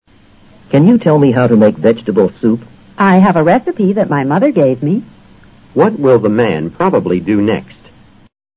托福听力小对话【101】make vegetable soup